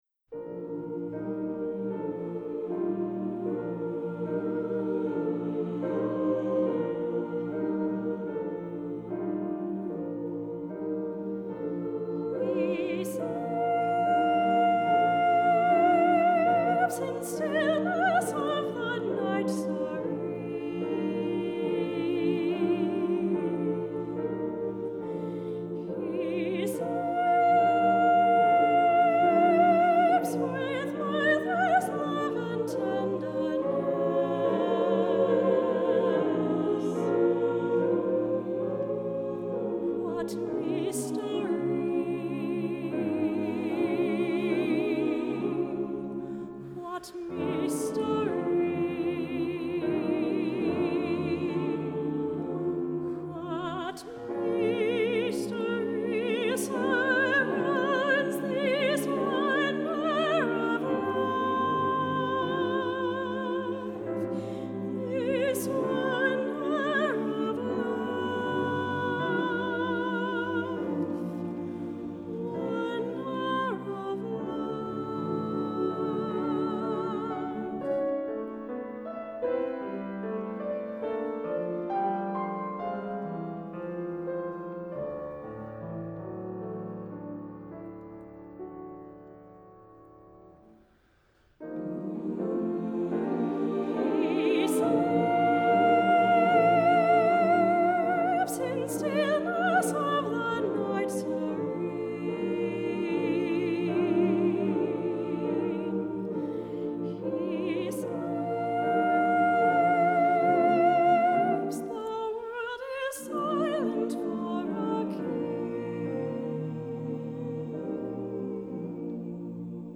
Voicing: SATB; Soprano Solo